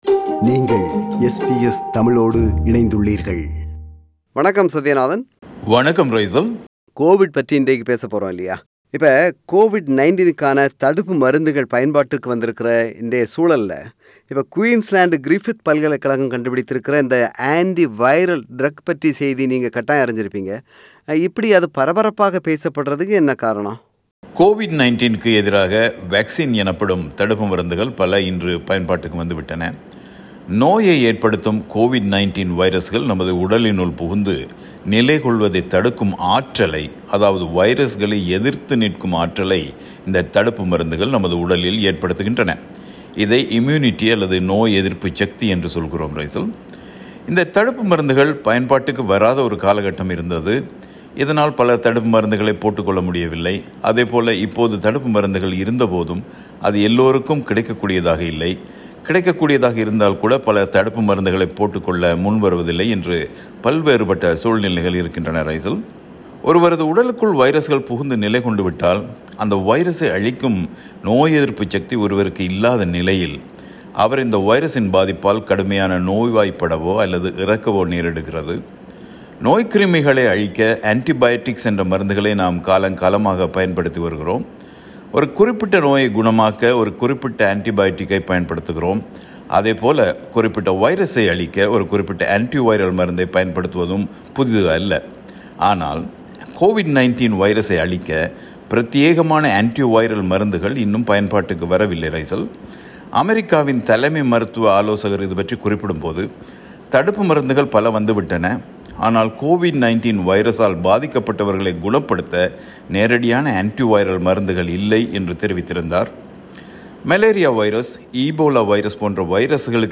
ஆனால் குயின்ஸ்லாந்து Griffith பல்லைக்கழக நிபுணர்களும் அமெரிக்க நிபுணர்களும் இணைந்து covid 19 வைரஸுக்கு எதிரான antiviral மருந்தொன்றைக் கண்டுபிடித்திருக்கிறார்கள் என்பது மருத்துவ விஞ்ஞான உலகில் மிகப் பரபரப்பான செய்தியாகப் பார்க்கப்படுகிறது. இதை விளக்குகிறார் விளக்குகிறார் பிரபல வானொலியாளர்